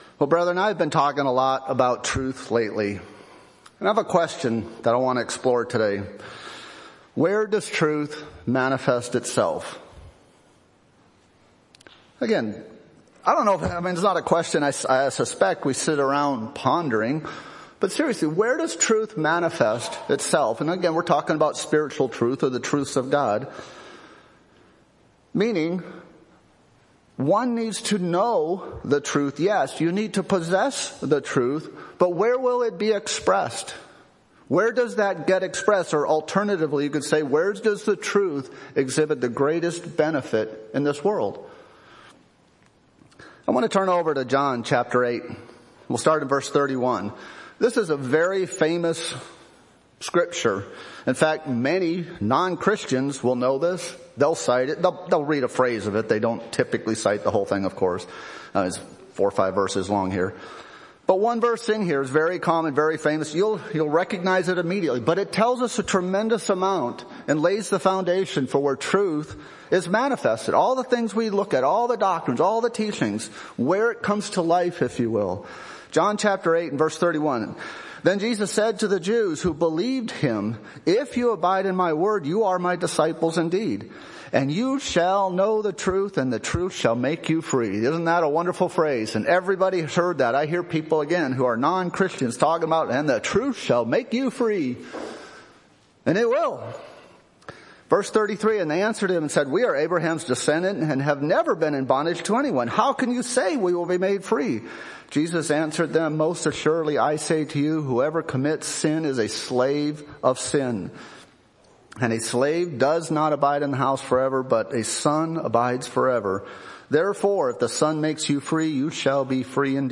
What is the source of truth in our lives, and what is the impact of that on our lives? Fourth in a series of sermons on truth.
Given in Nashville, TN